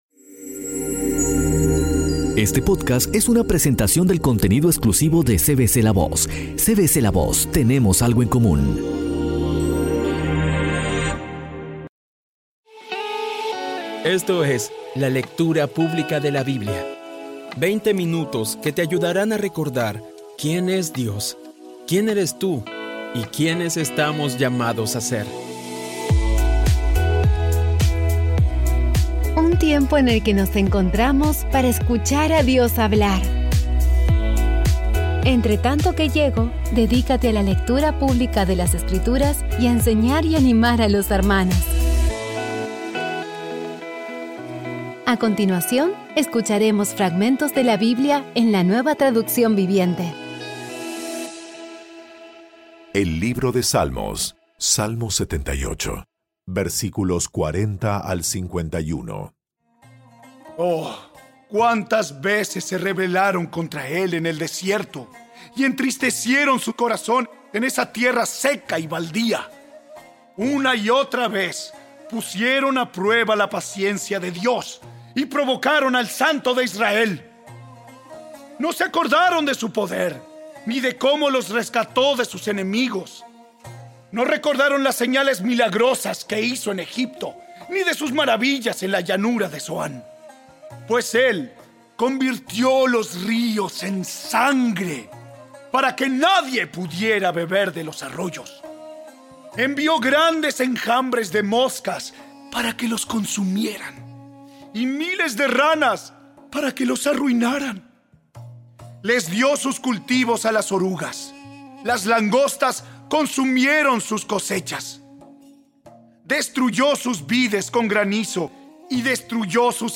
Audio Biblia Dramatizada Episodio 186
Poco a poco y con las maravillosas voces actuadas de los protagonistas vas degustando las palabras de esa guía que Dios nos dio.